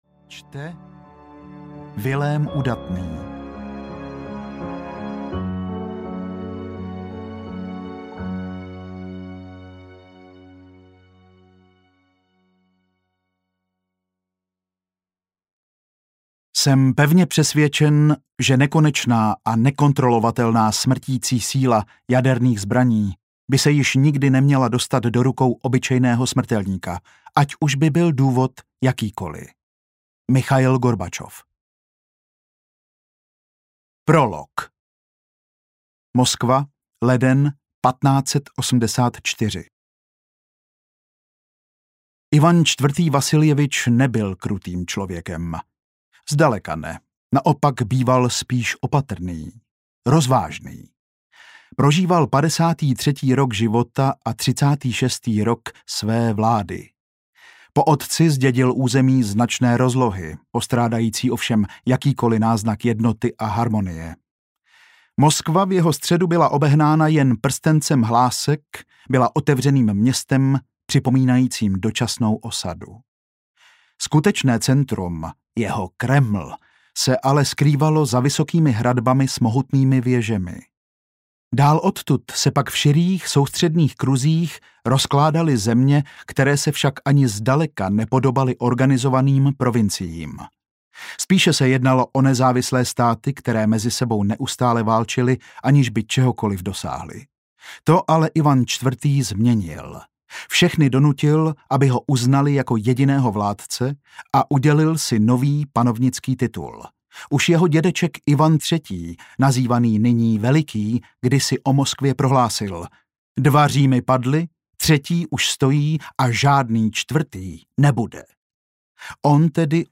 Padá rudá hvězda audiokniha
Ukázka z knihy